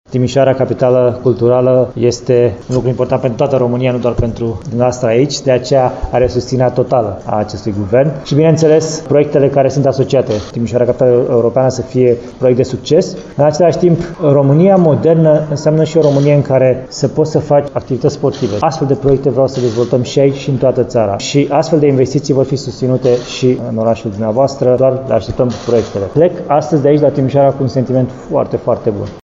La Timișoara, premierul Florin Cîţu a prezentat moţiunea de candidat la preşedinţia partidului în faţa membrilor PNL Timiş.